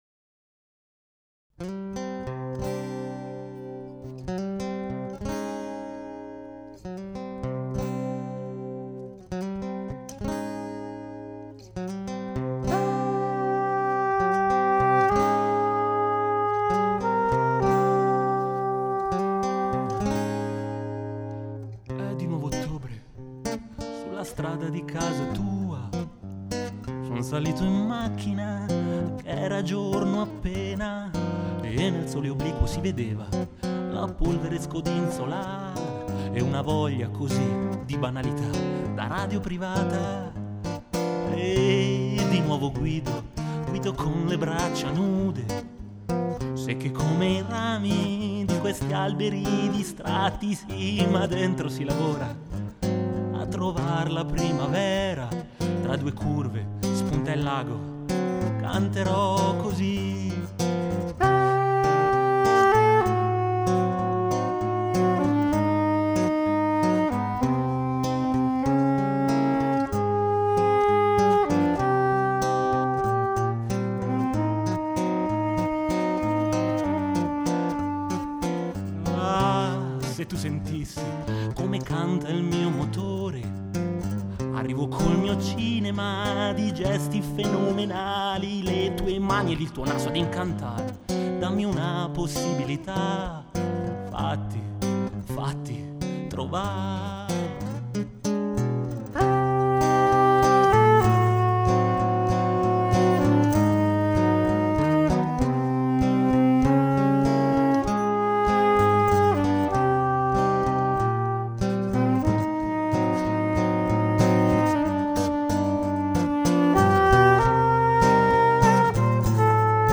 Ancora una canzoncina tristuccia-sentimental in versione acustica (magagne comprese...).
L'unico problema è che trovo la voce un po' bassina (di volume) rispetto agli altri strumenti, ma giusto un pochino.
è tutta suonata in presa diretta, forse la mia scarsa abilità nel canto viene un po' minata dal fatto che non potevo riprendere mai fiato (perché quando non cantavo, suonavo le parti strumentali al sax)...